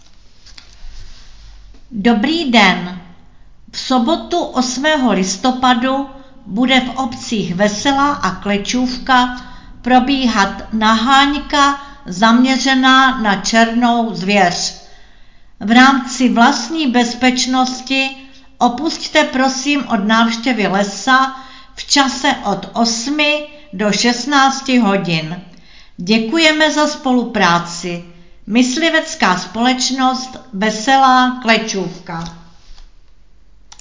Hlášení místního rozhlasu
Hlášení ze dne 6.11.2025